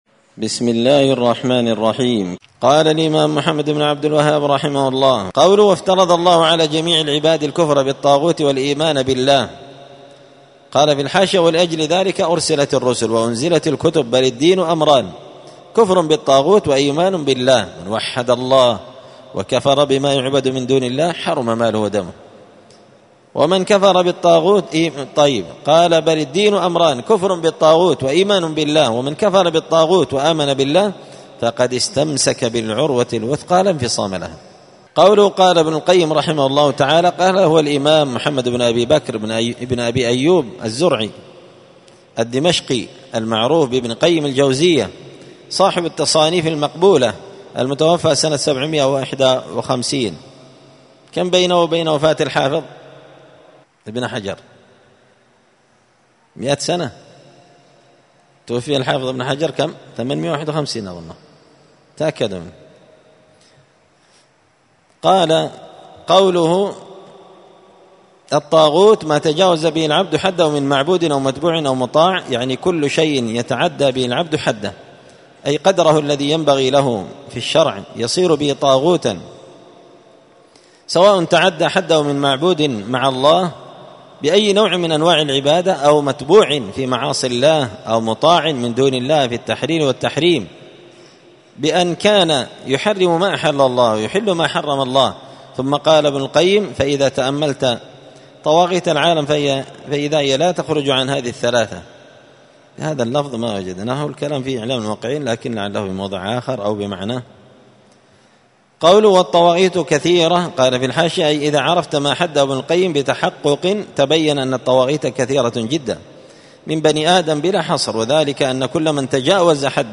دار الحديث السلفية بمسجد الفرقان بقشن المهرة اليمن
*الدرس الأربعون والأخير (40) من قوله {وافترض الله على جميع العباد الكفر بالطاغوت والإيمان بالله…}*